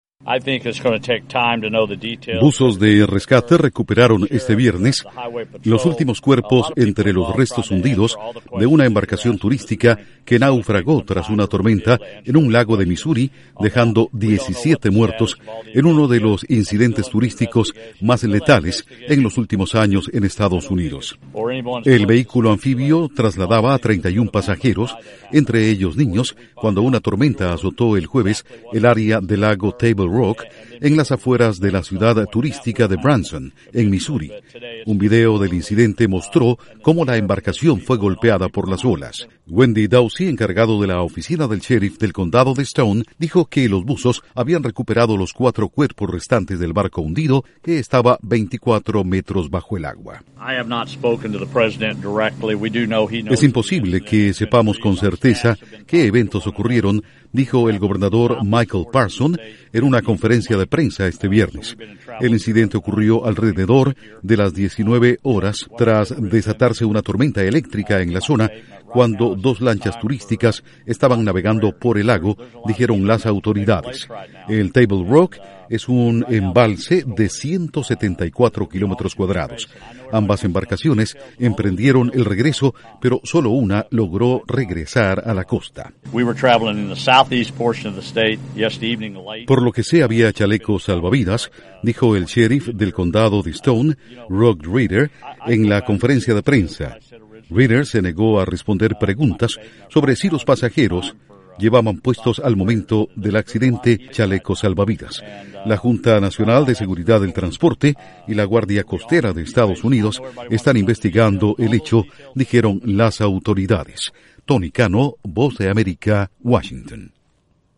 Diecisiete muertos tras naufragio de embarcación turística por tormenta en Misuri. Informa desde la Voz de América en Washington